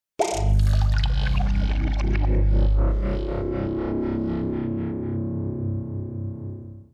Звуки трансформеров
Звуковые эффекты в кино